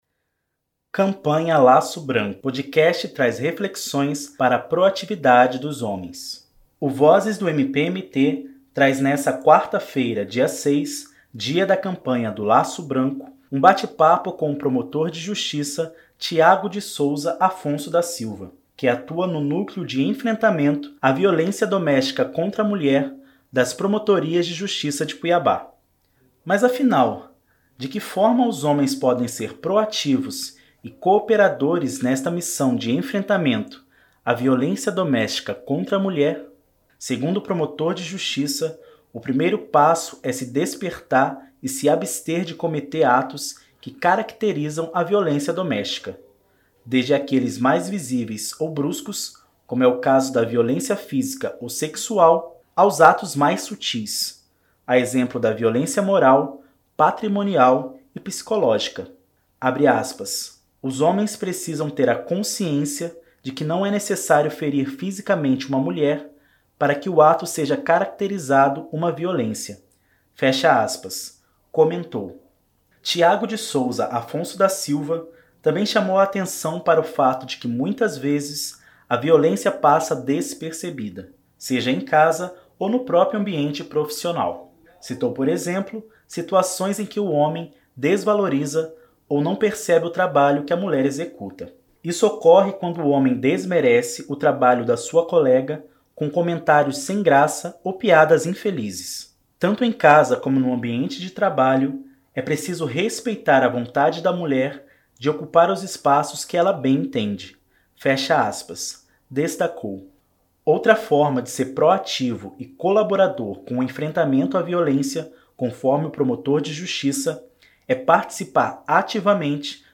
O Vozes do MPMT traz nesta quarta-feira (06), dia da Campanha do Laço Branco, um bate-papo com o promotor de Justiça Tiago de Sousa Afonso da Silva, que atua no Núcleo de Enfrentamento à Violência Doméstica Contra a Mulher das Promotorias de Justiça de Cuiabá. Mas afinal, de que forma os homens podem ser proativos e cooperadores nesta missão de enfrentamento à violência doméstica contra a mulher?